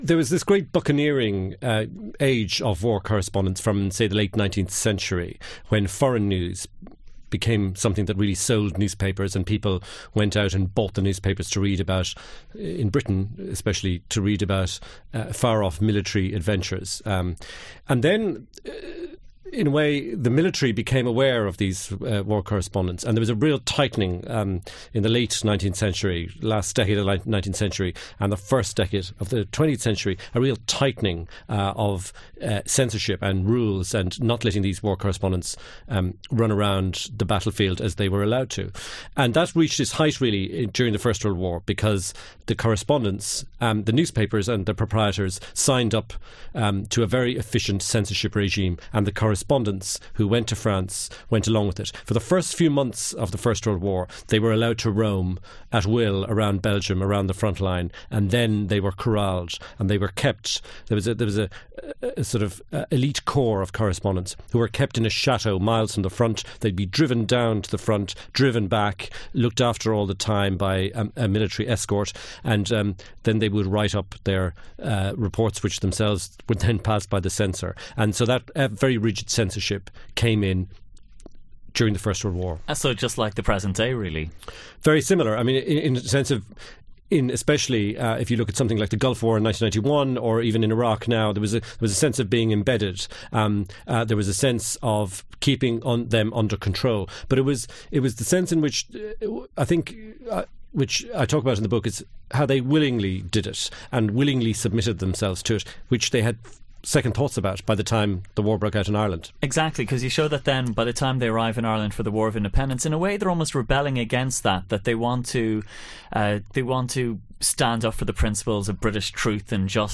Books